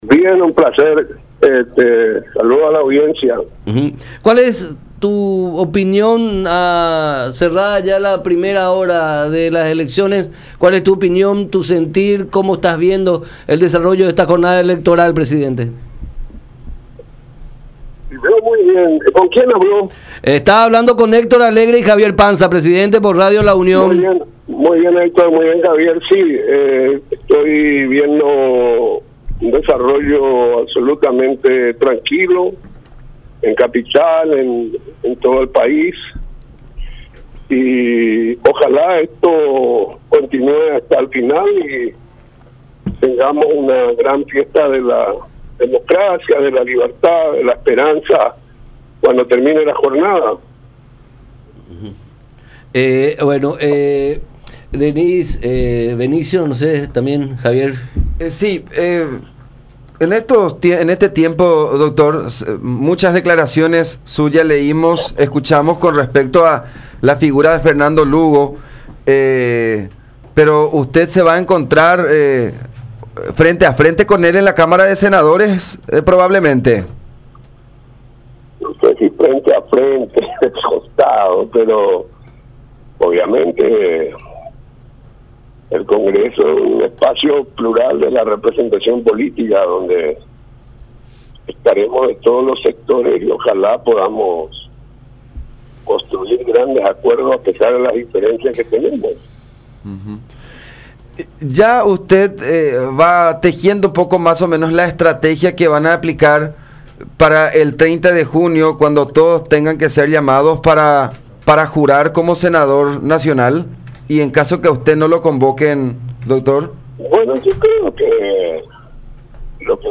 Vamos a ver primero cómo terminan las elecciones esta noche”, expuso en contacto con La Unión R800 AM.